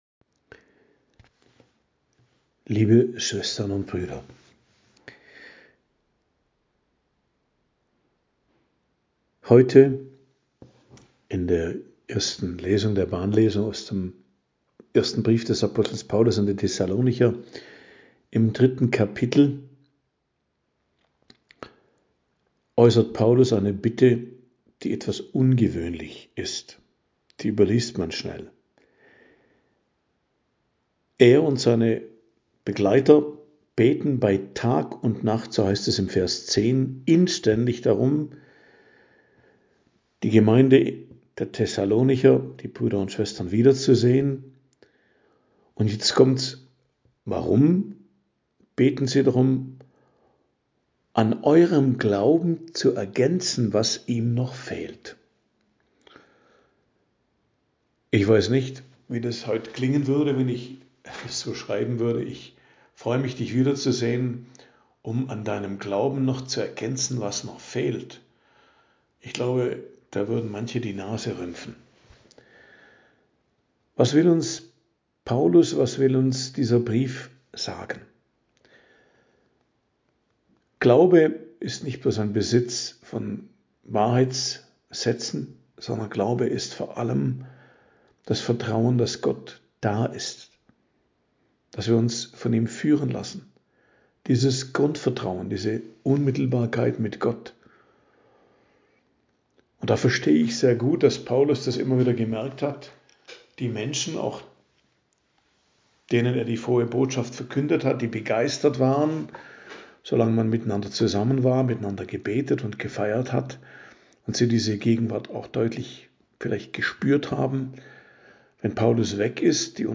Predigt am Donnerstag der 21. Woche i.J., 28.08.2025